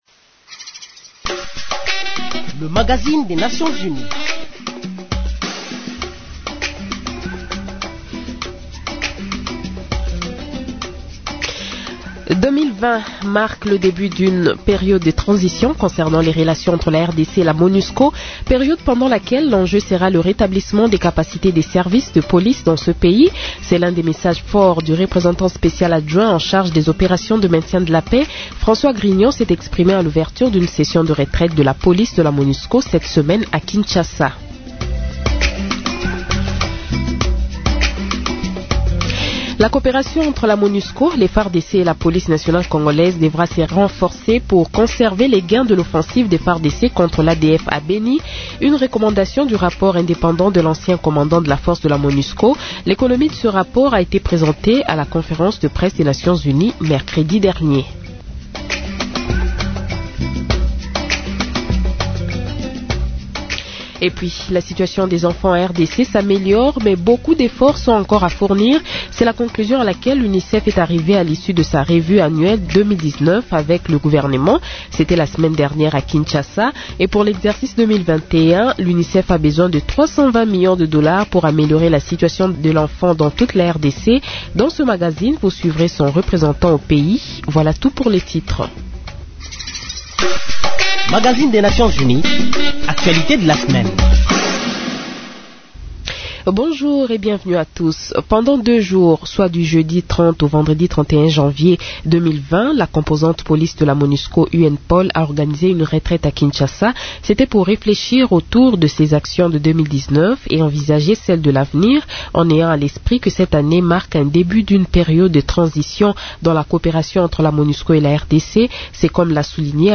Ce magazine revient sur l’entretien que Mme Bachelet a accordé en exclusivité à Radio Okapi, dans lequel elle évoque les enjeux de sa mission en RDC.